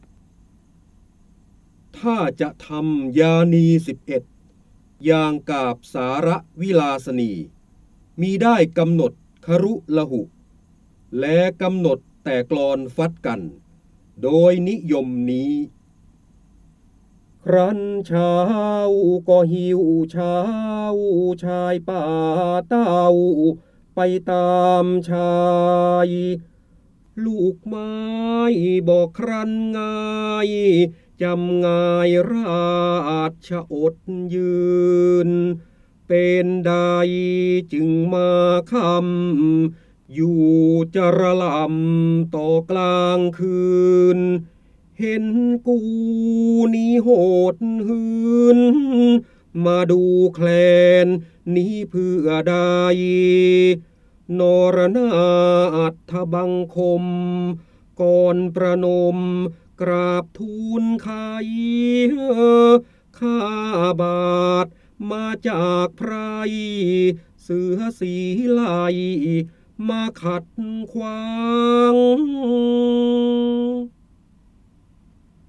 เสียงบรรยายจากหนังสือ จินดามณี (พระโหราธิบดี) ถ้าจะทำยานี๑๑
คำสำคัญ : พระเจ้าบรมโกศ, การอ่านออกเสียง, พระโหราธิบดี, ร้อยกรอง, ร้อยแก้ว, จินดามณี